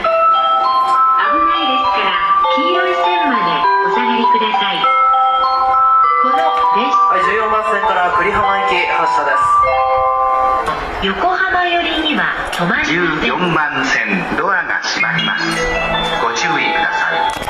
しかしながら、横須賀線ホームは非常に静かです。
周りが静かです。